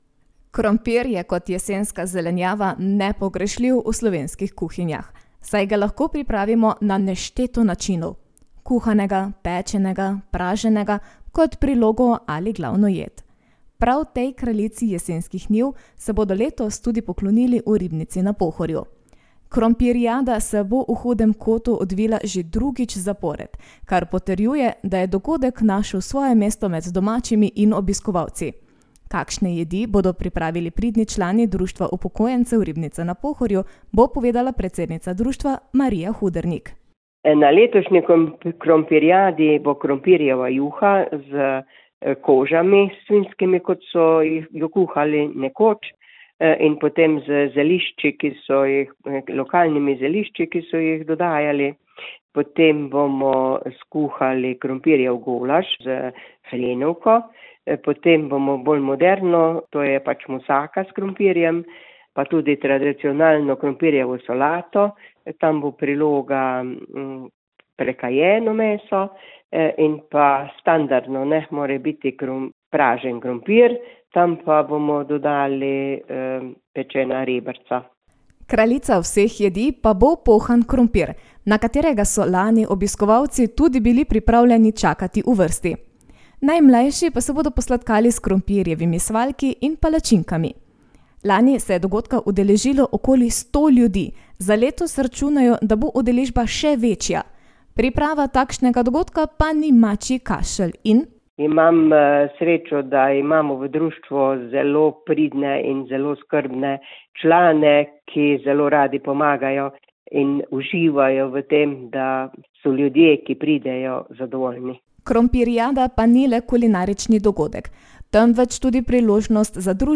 Poroča